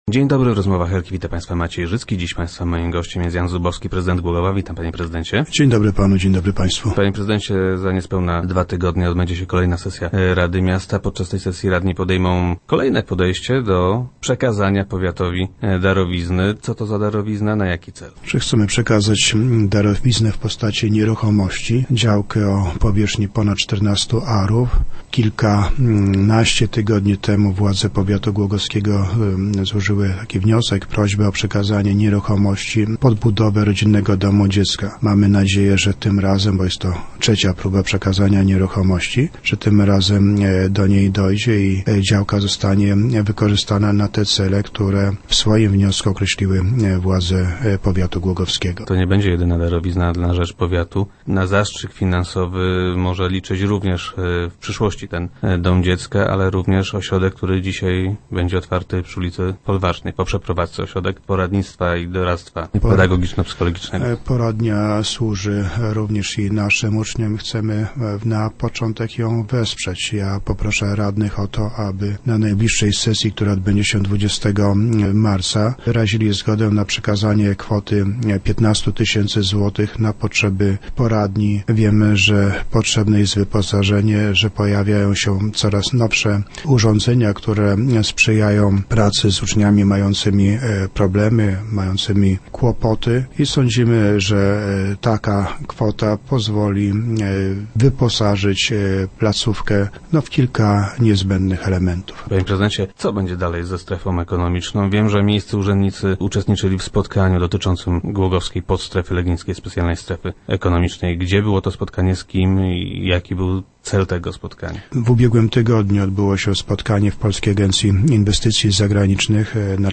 - Rozmowy dotyczyły promocji głogowskie strefy. Poprosiliśmy również o to, by z zewnątrz oceniono, które z naszych działań są niewłaściwe. Otrzymaliśmy odpowiedź, że podejmujemy takie same działania, jakie podejmują władze innych samorządów oraz właściciele terenów, na których funkcjonują strefy ekonomiczne - mówi prezydent Zubowski, który był gościem Rozmów Elki.